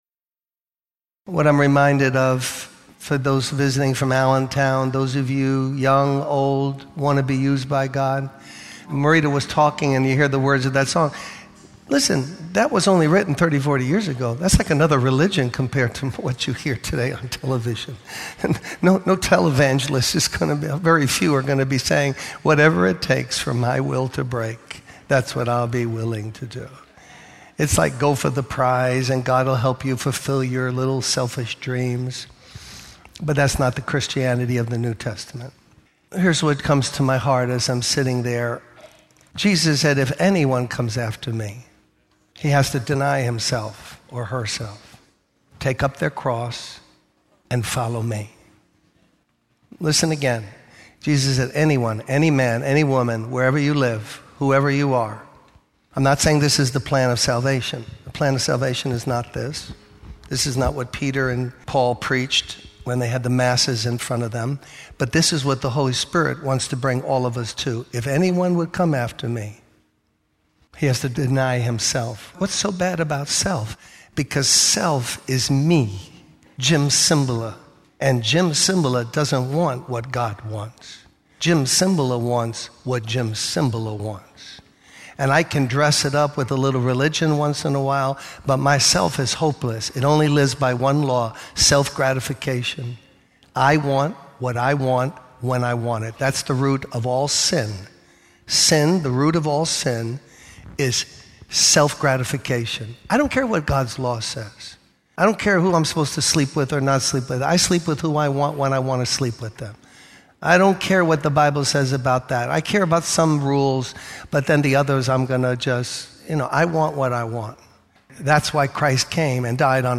In this sermon, the speaker reflects on his personal journey from the business world to the ministry and the battle he faced in accepting God's calling. He emphasizes that our ways and thoughts are not the same as God's, and that true Christianity requires denying oneself, taking up the cross, and following Jesus.